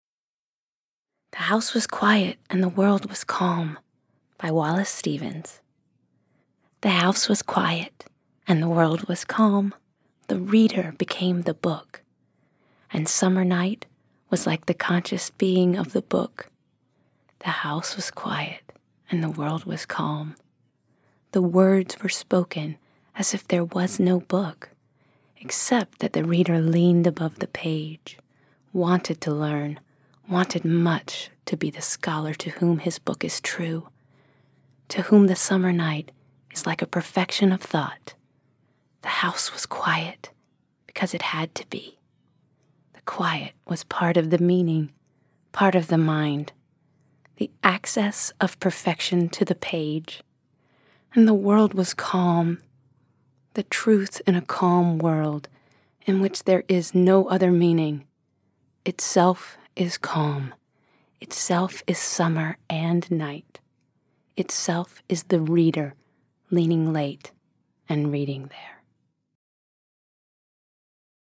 Lauren Kennedy reads "The House Was Quiet and the World Was Calm" by Wallace Stevens
In celebration of National Poetry Month, every day we're posting a new poem from the spoken-word album Poetic License, a three-CD set that features one hundred performers of stage and screen reading one hundred poems selected by the actors themselves.
Lauren Kennedy, a singer and actress, has played the Lady of the Lake in Monty Python's Spamalot on Broadway and Nellie Forbush in the West End production of South Pacific, as well as roles in Les Miserables, Side Show, and Sunset Boulevard.